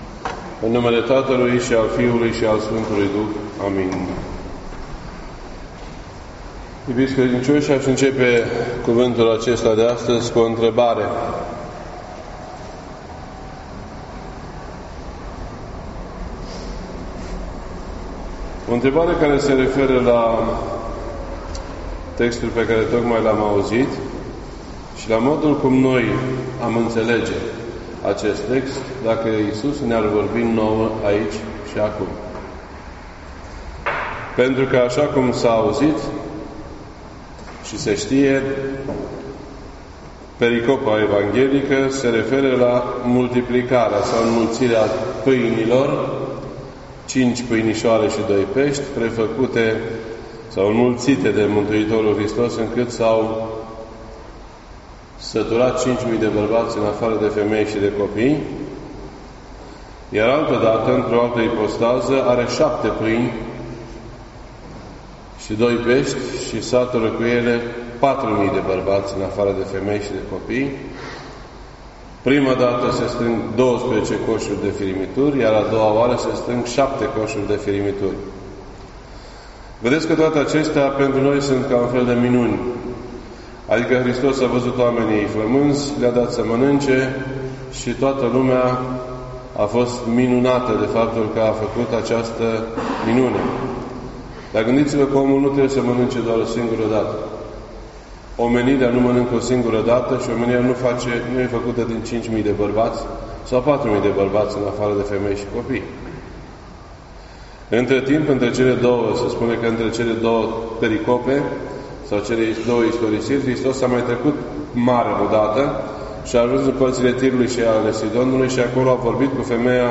This entry was posted on Sunday, July 22nd, 2018 at 1:29 PM and is filed under Predici ortodoxe in format audio.